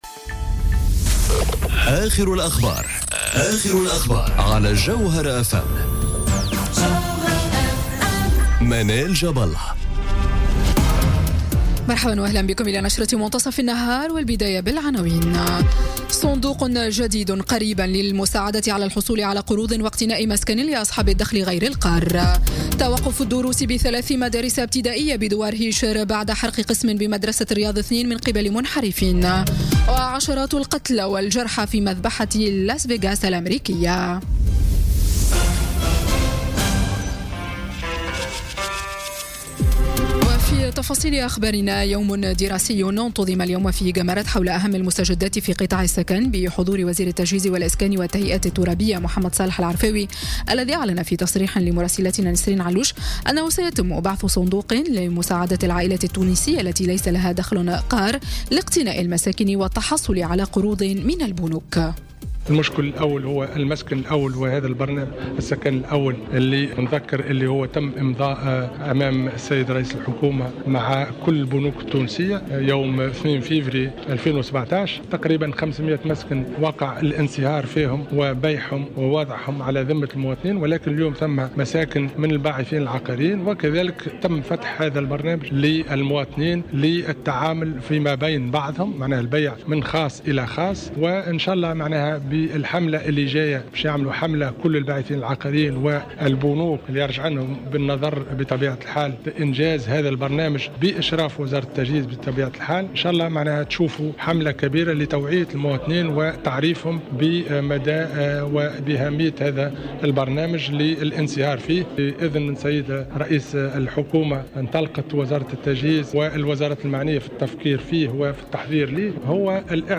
نشرة أخبار منتصف النهار ليوم الإثنين 2 أكتوبر 2017